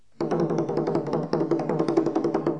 4.1.2.2. TRỐNG CƠM